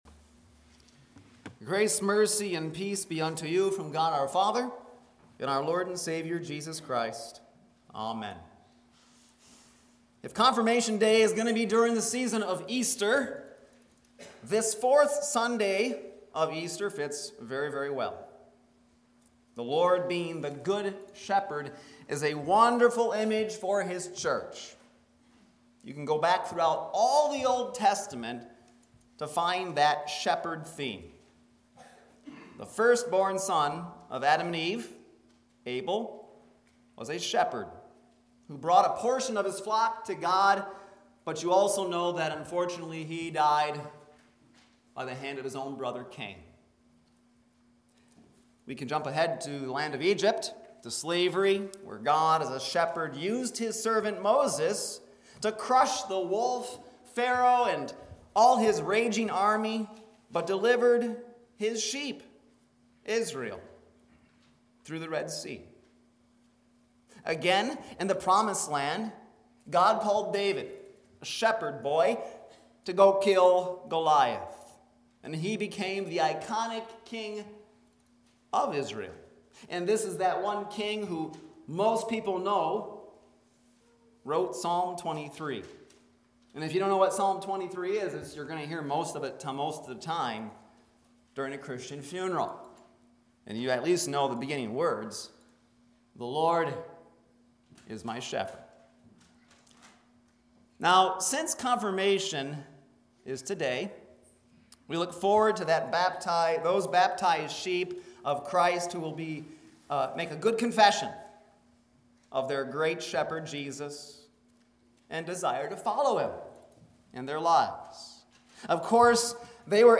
Sermons 4th Sunday of Easter 2018